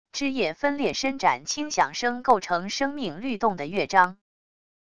枝叶分裂伸展轻响声构成生命律动的乐章wav音频